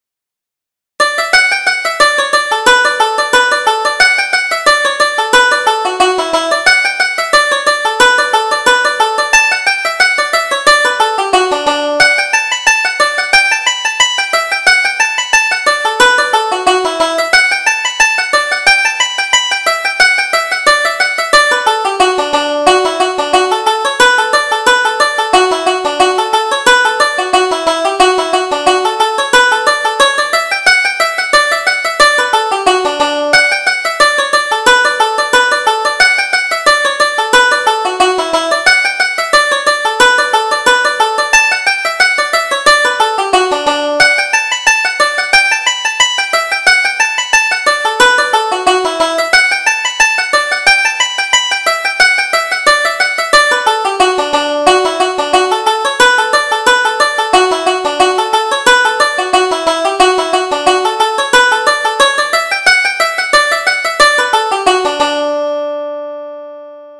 Reel: The Galbally Lasses